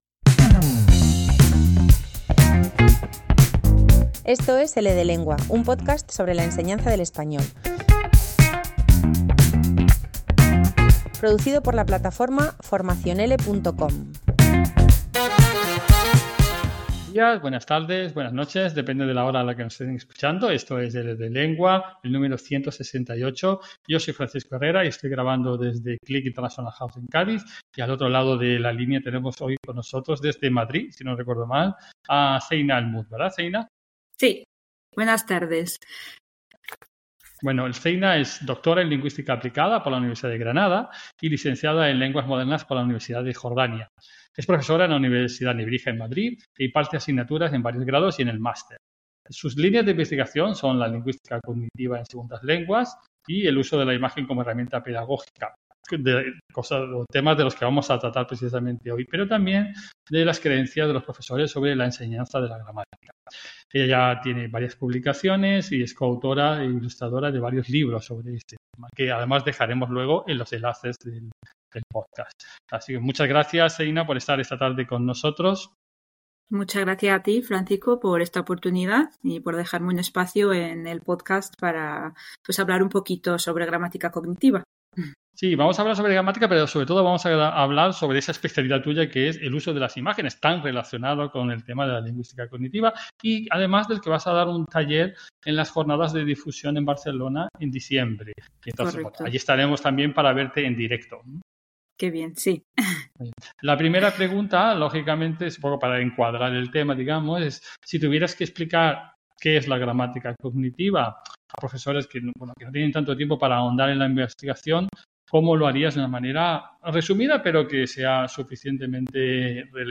Una charla
Entrevista